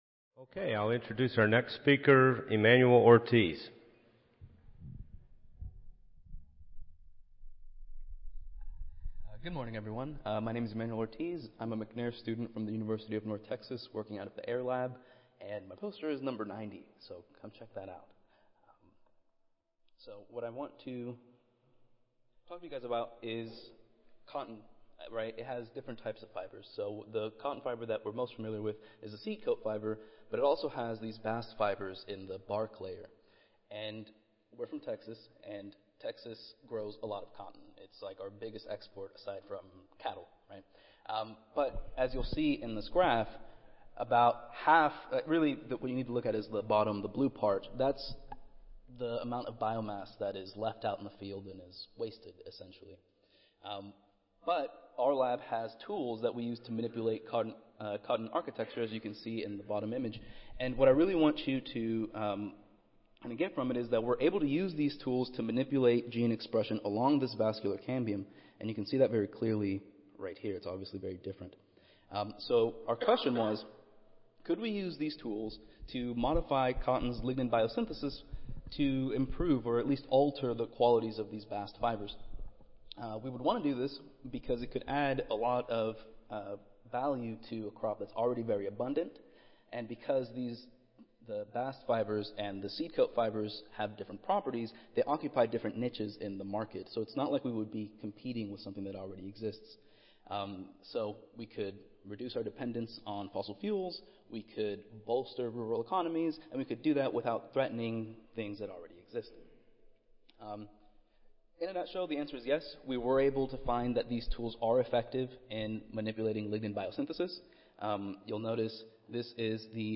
Cotton Improvement - Lightning Talk Student Competition
Audio File Recorded Presentation